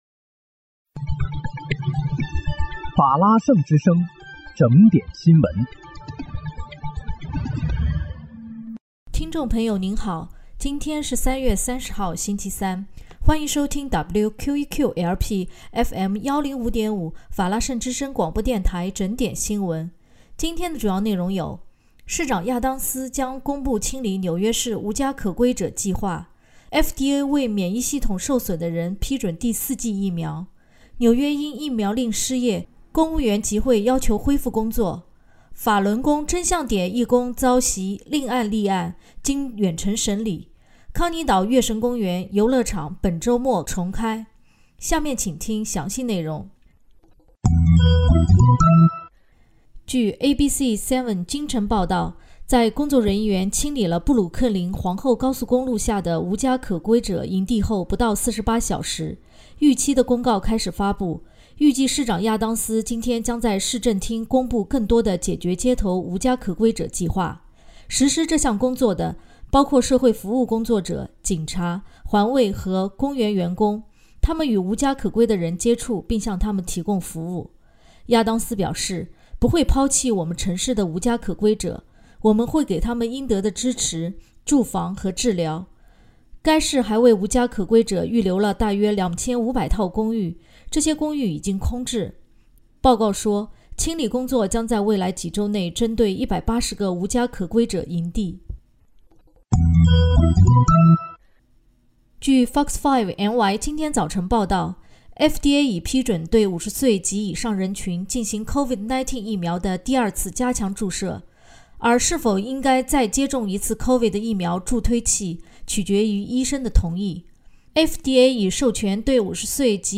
3月30日（星期三）纽约整点新闻